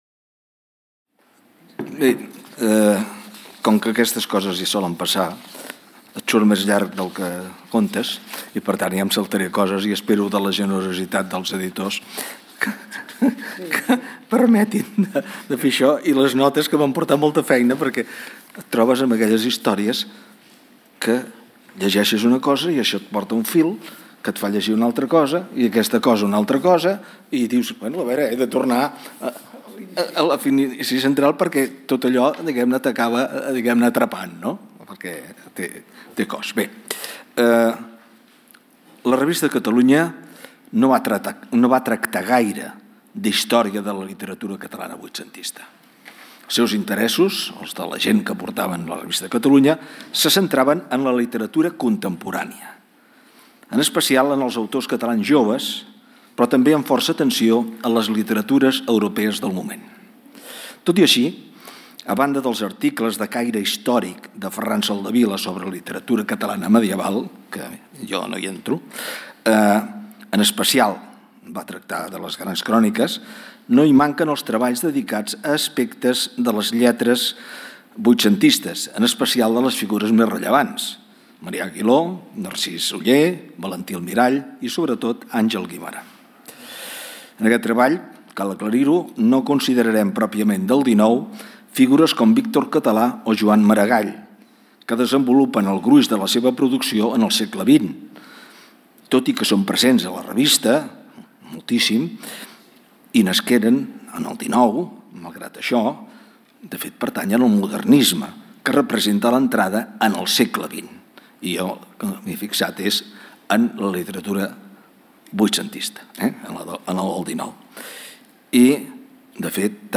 Comunicacions
en el marc del Simposi Trias 2024 sobre el centenari de la Revista de Catalunya organitzat per la Càtedra Ferrater Mora de la Universitat de Girona